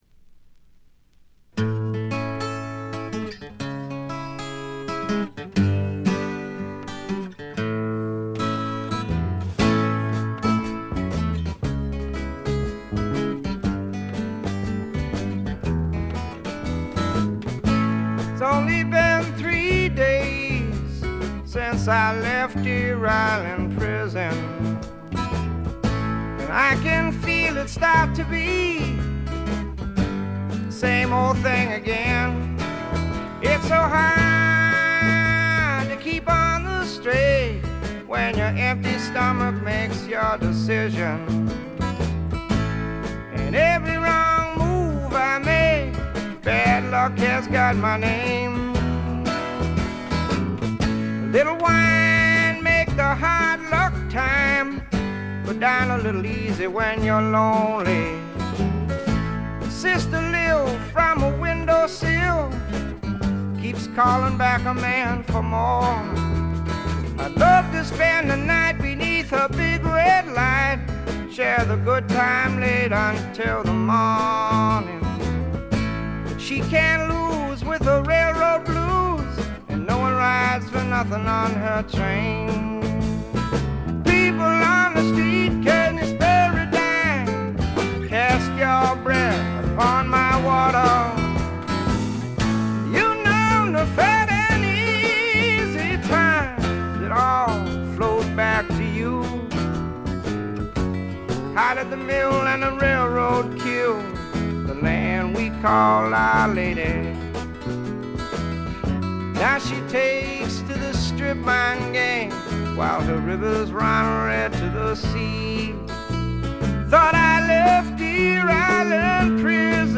ごくわずかなチリプチ程度。
試聴曲は現品からの取り込み音源です。
Vocals, Guitar, Harmonica
Dobro, Guitar
Keyboards
Bass
Drums